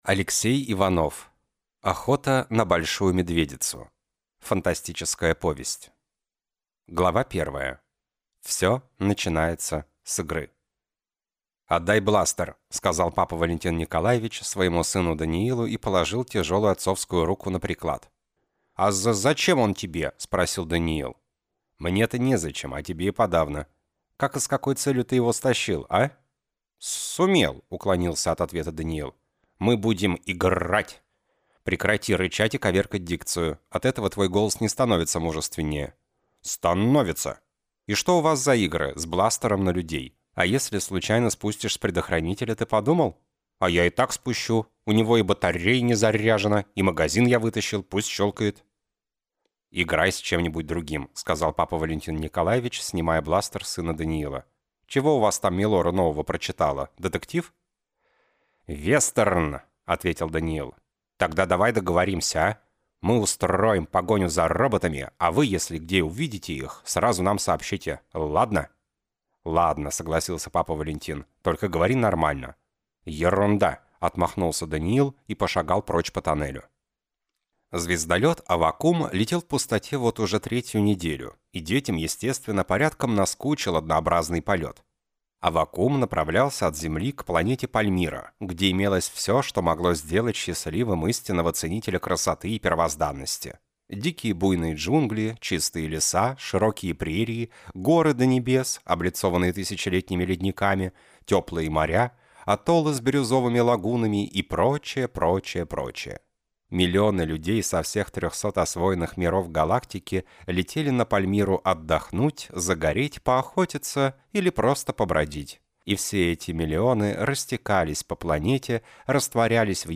Аудиокнига Охота на «Большую Медведицу» | Библиотека аудиокниг